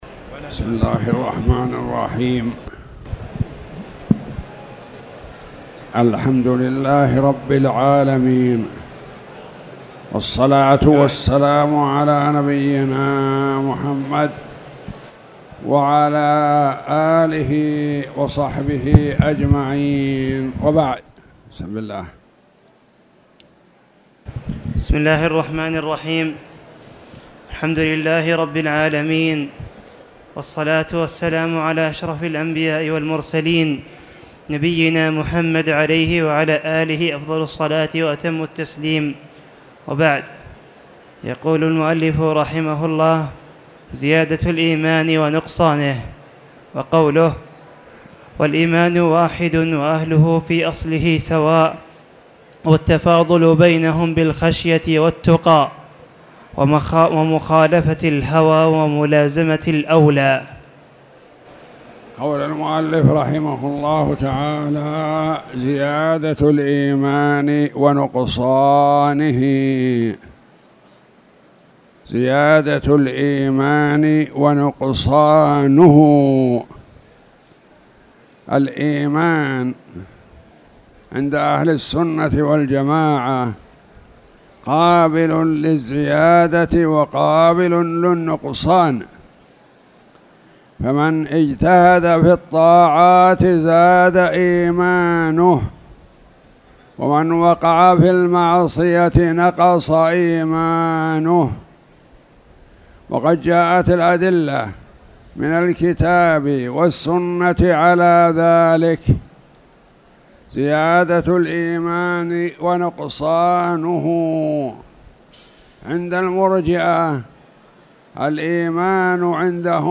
تاريخ النشر ١٢ ذو القعدة ١٤٣٧ هـ المكان: المسجد الحرام الشيخ